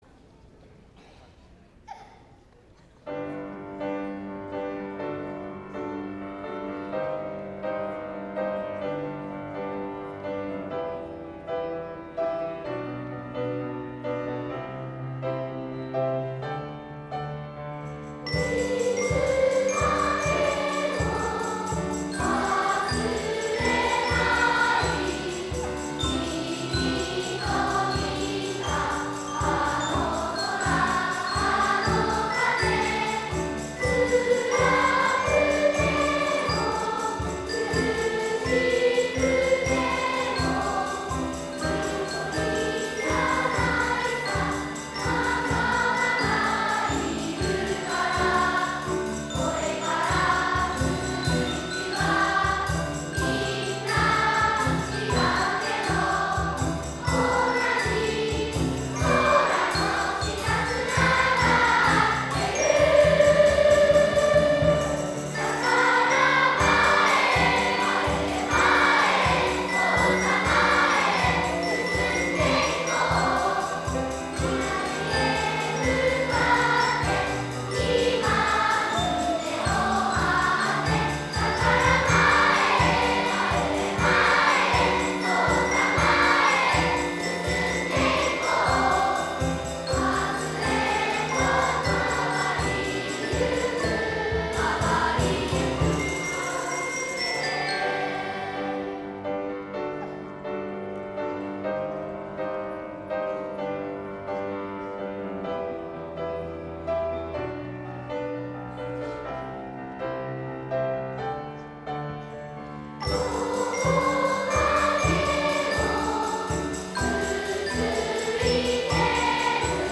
11月18日、藤沢市民会館にて『2015年度　湘南学園小学校音楽会』が開催されました。
歌声は大ホールに響き渡り、素晴らしいハーモニーを奏でました。
全校合唱『ぼくらの未来へ』
６年生がステージに立ち、１年生から５年生と向き合う形で歌います。